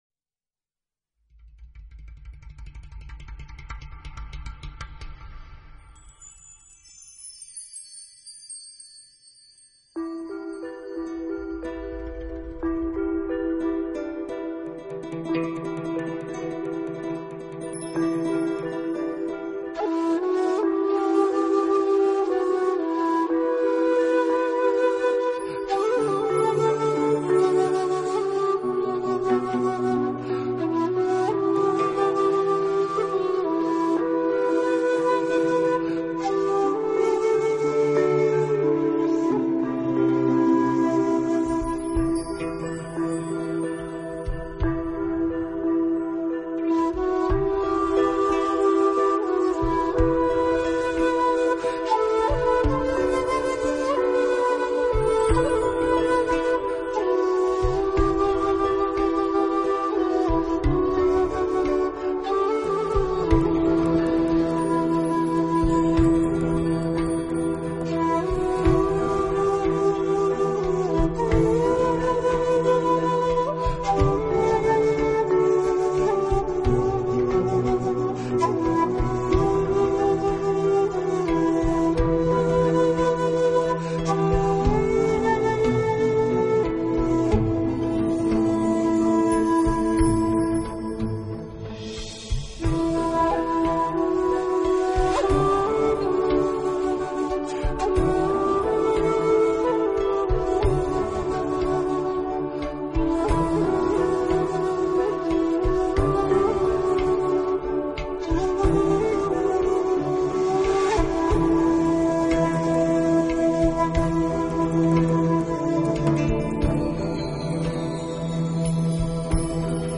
类型：NEWAGE